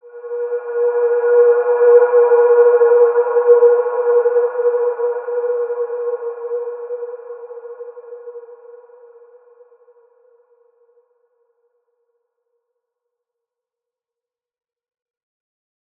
Large-Space-B4-mf.wav